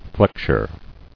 [flex·ure]